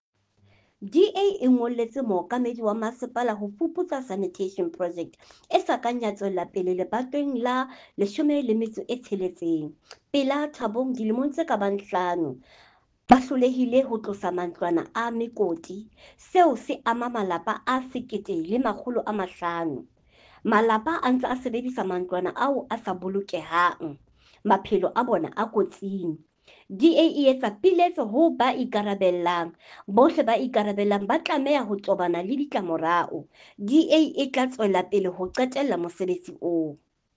Sesotho soundbite by Cllr Florence Bernado.